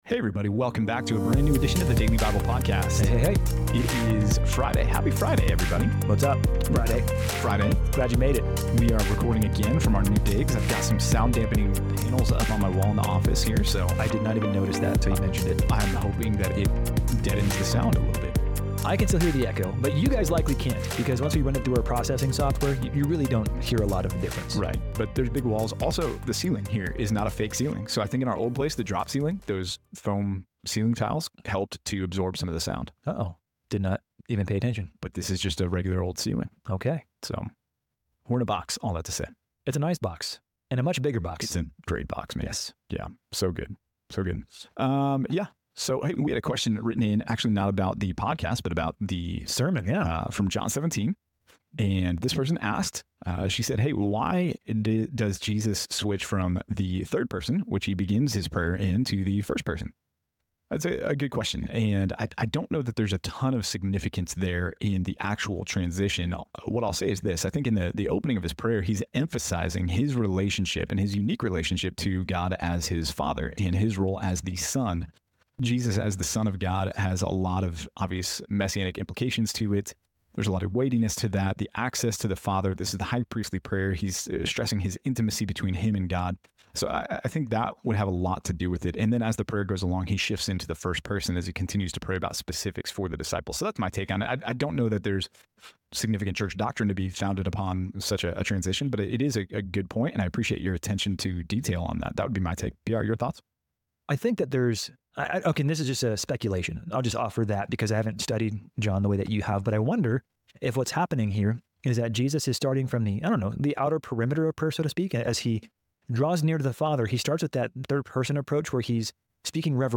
Sound Dampening and New Recording Setup